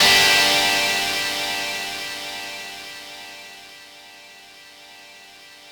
ChordGm.wav